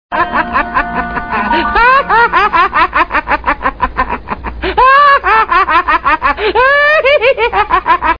周星驰特色笑声音效免费音频素材下载